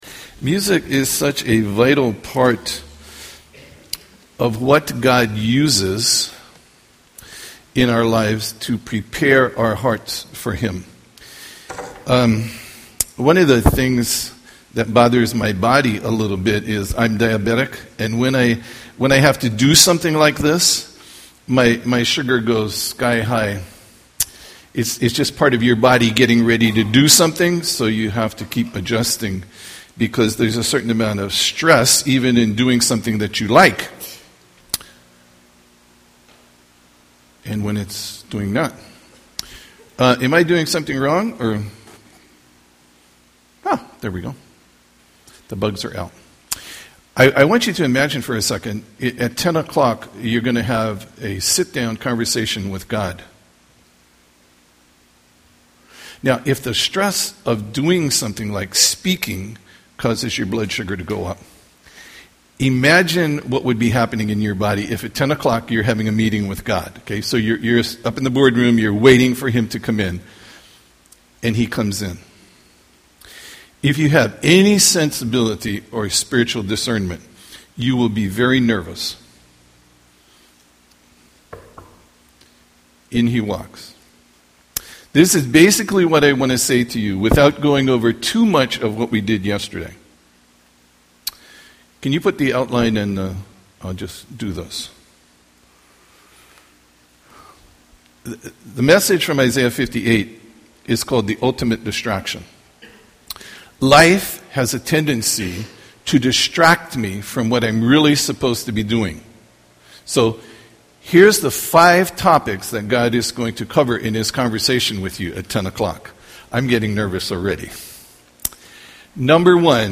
NBBI Chapel https